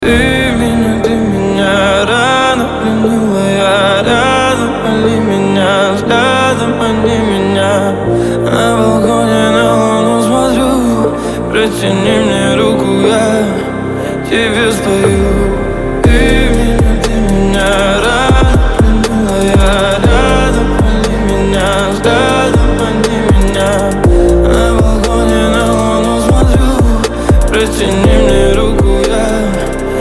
• Качество: 320, Stereo
мужской вокал
лирика
спокойные
басы